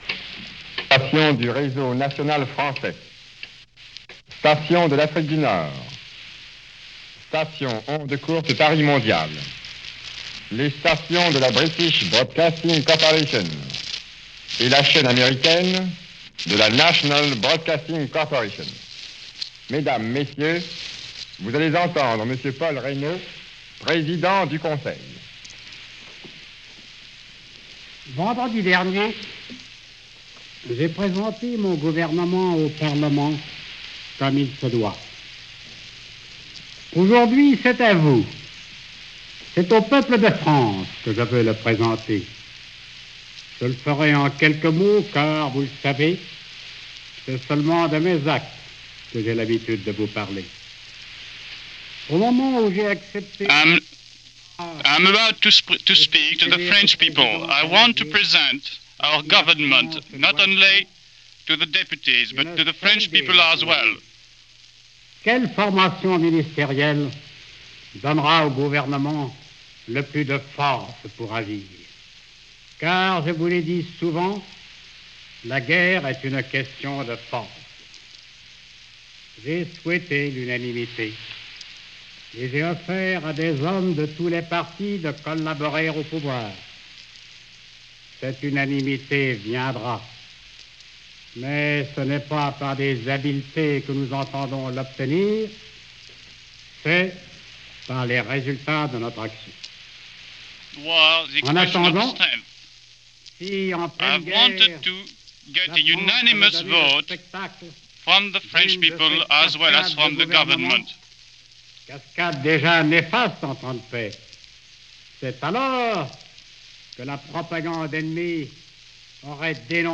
Prime Minister Paul Reynaud - Address From Paris - March 1940 - the first address by newly appointed French Prime Minister Paul Reynaud.